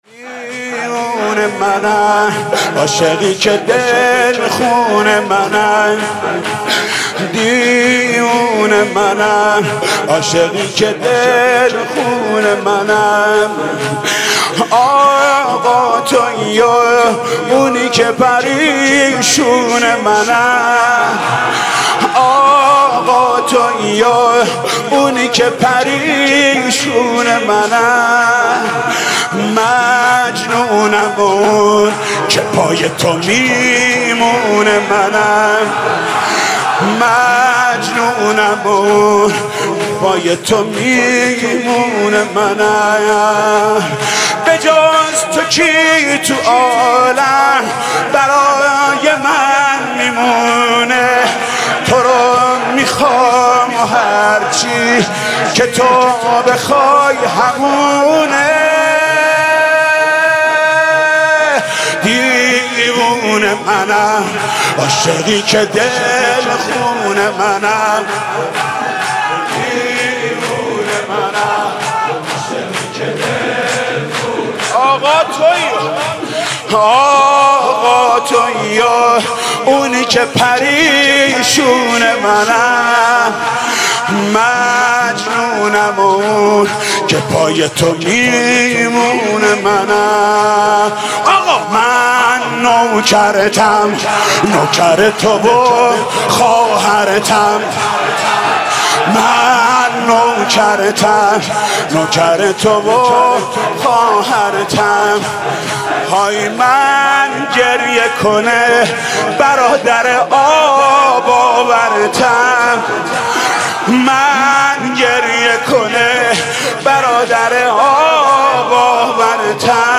مناسبت : شب چهارم محرم
مداح : محمود کریمی قالب : شور